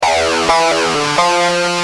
Lead_a1.wav